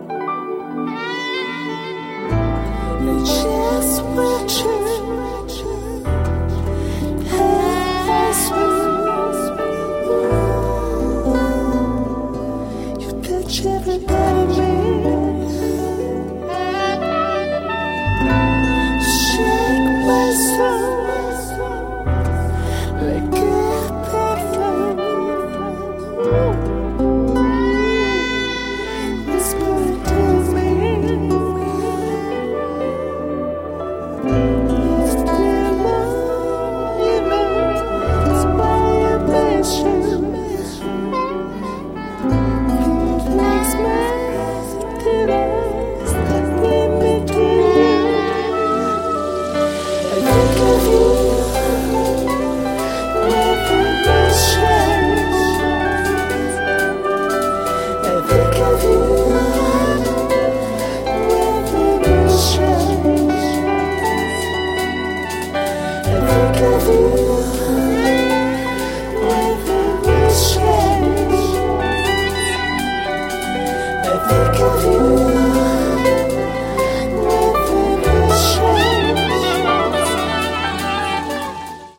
甘美なファルセット・ヴォイス＆オーガニック・グルーヴ
南国の楽園に導かれるようなトロピカルで官能的なメロウ・ディープ・ハウス〜ダウン・テンポ。